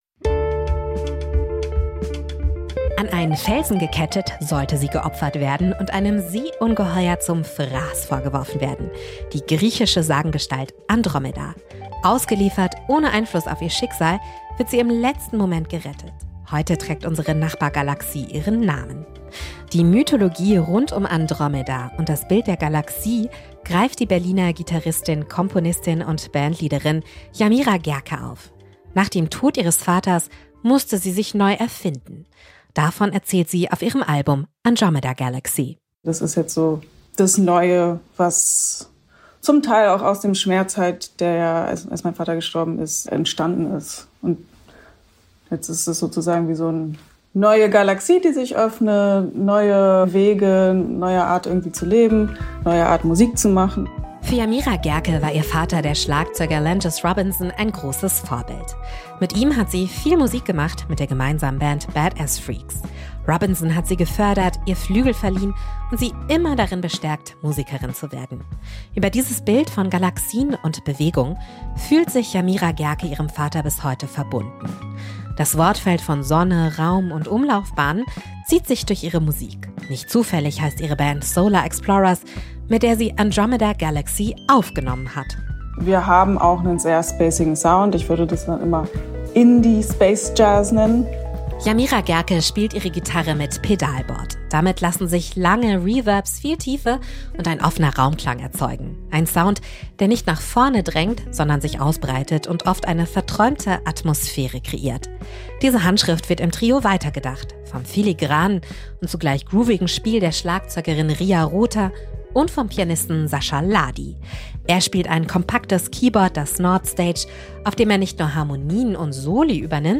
Album Tipp Jazz
der schwebende Sound von „Andromeda Galaxy“ lässt zudem Elemente aus Jazz, Blues und Ghospel erkennen.